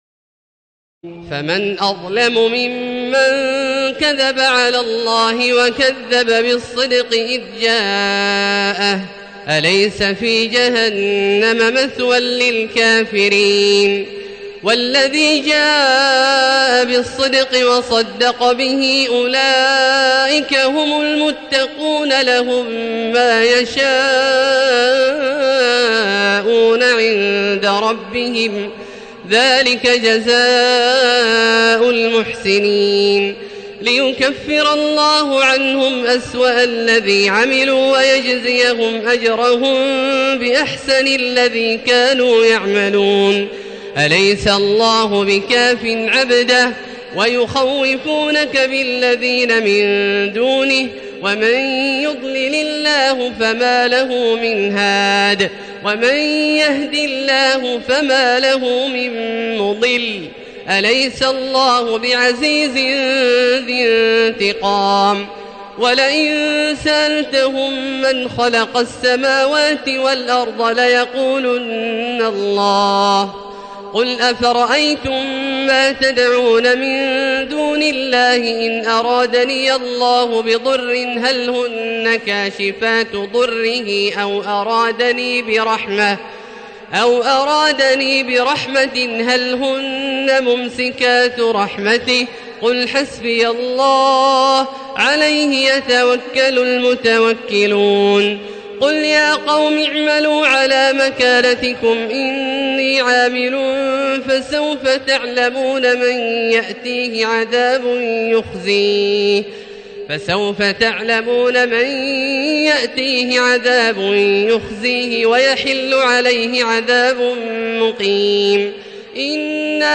تراويح ليلة 23 رمضان 1437هـ من سور الزمر (32-75) و غافر (1-46) Taraweeh 23 st night Ramadan 1437H from Surah Az-Zumar and Ghaafir > تراويح الحرم المكي عام 1437 🕋 > التراويح - تلاوات الحرمين